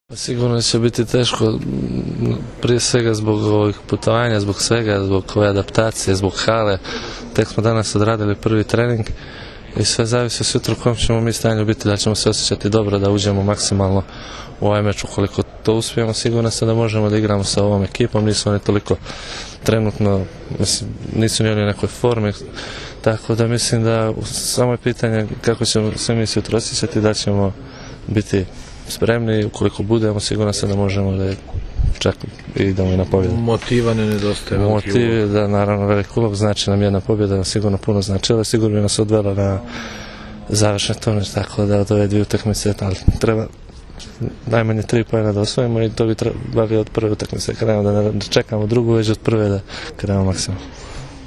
IZJAVA MILOŠA NIKIĆA